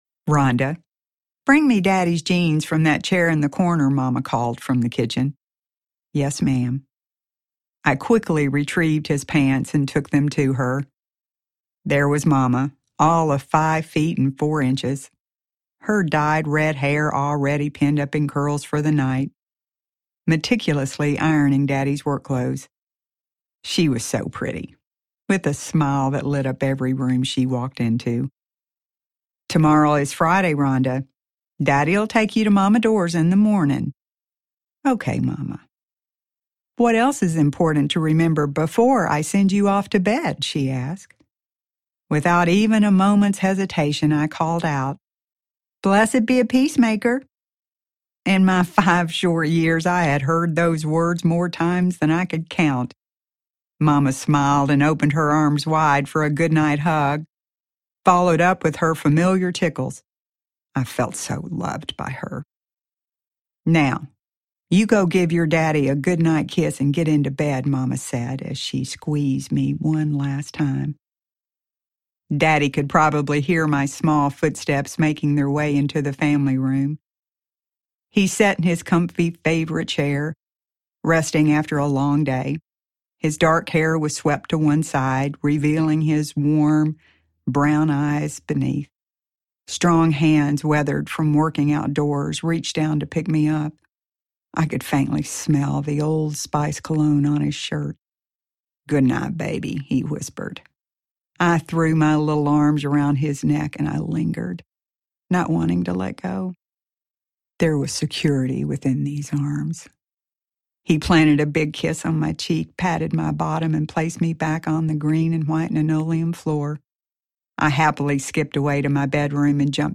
Hindsight – Audiobook Audiobook
Hindsight Audiobook TN Sample.mp3